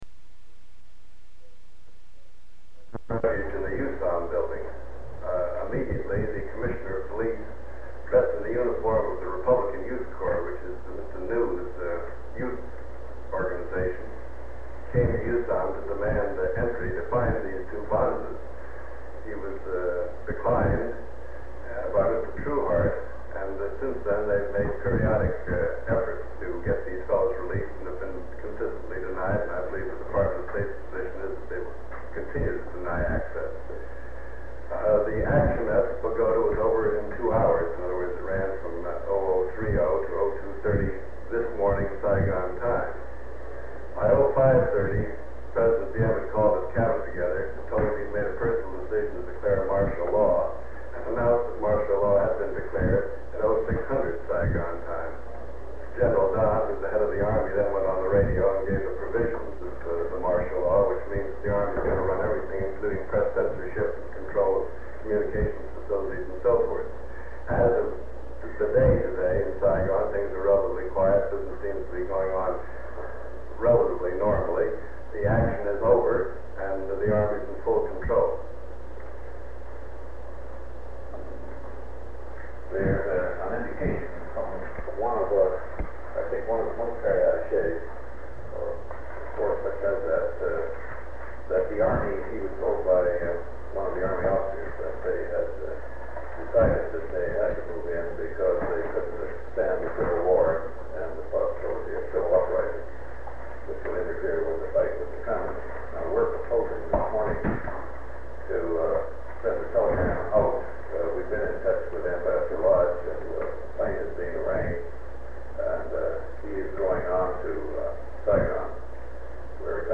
Sound recording of a meeting held on August 21, 1963, between President John F. Kennedy, Specialist on Counterinsurgency for the Joint Chiefs of Staff Victor Krulak, Under Secretary of State George Ball, and Under Secretary of State for Political Affairs W. Averell Harriman. They review the situation in Vietnam. They discuss cables and press conferences, Vietnamese leadership, and religious freedom issues.
Secret White House Tapes | John F. Kennedy Presidency Meetings: Tape 106/A41.